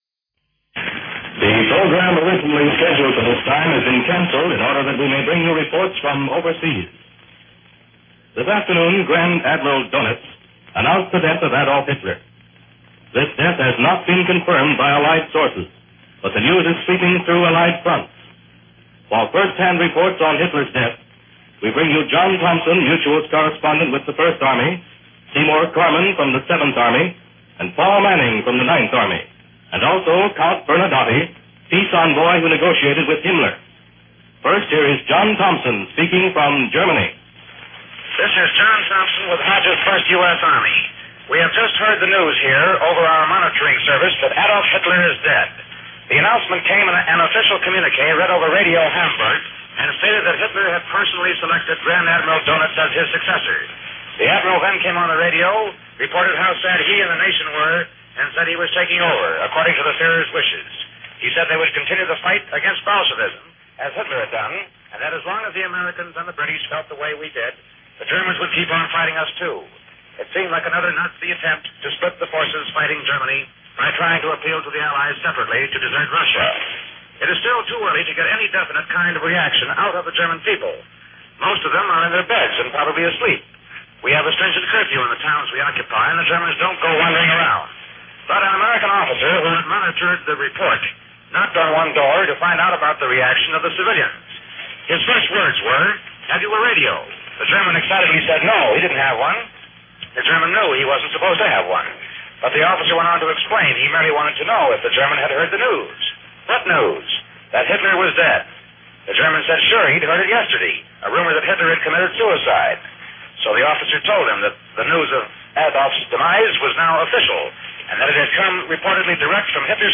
Here is a special program presented by Mutual Broadcasting shortly after Hitler’s death was reported and confirmed – along with correspondents filing reports on reactions throughout Europe.